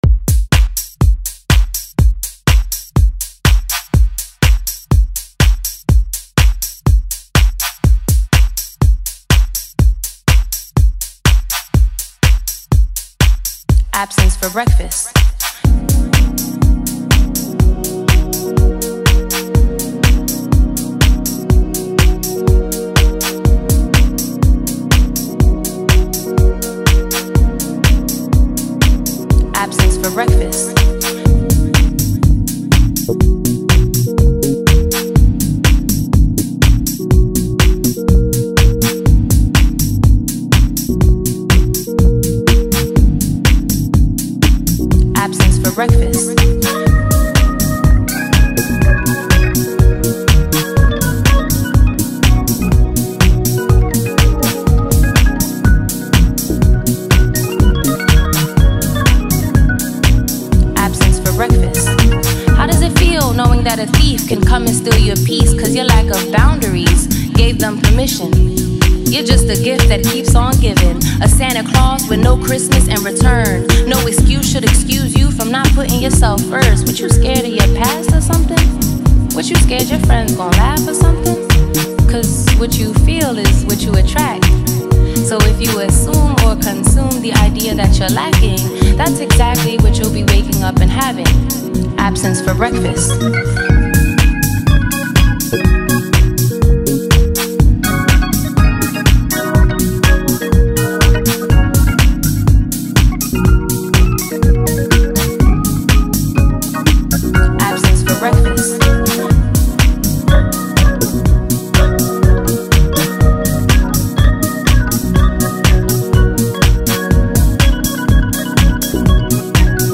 smooth and soulful vocals